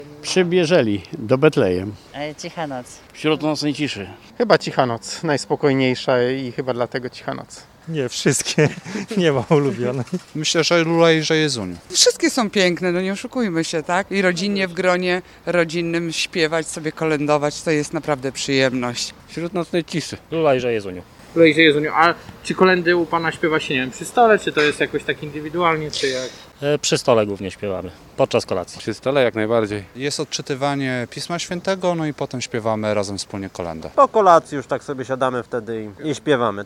Zielonogórzanie kolędują na święta
Jakie są ulubione kolędy zielonogórzan? Jak je śpiewają? – Najczęściej przy stole z rodziną – mówią mieszkańcy: